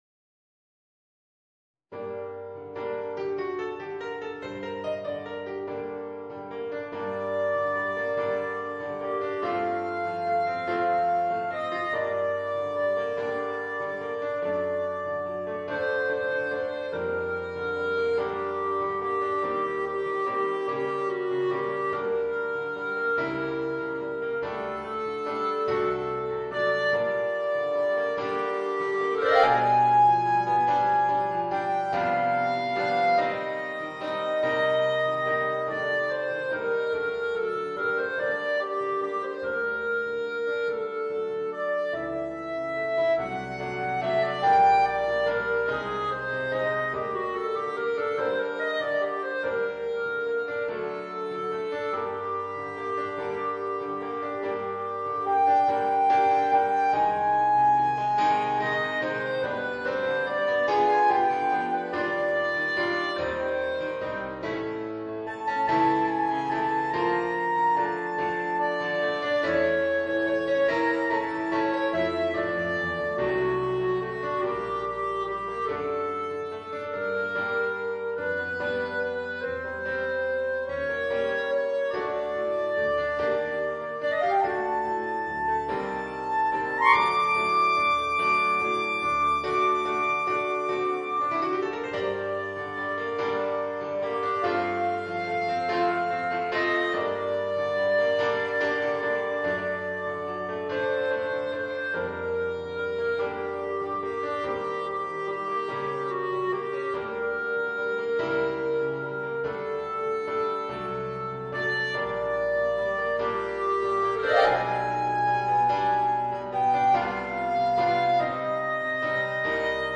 Voicing: Clarinet and Piano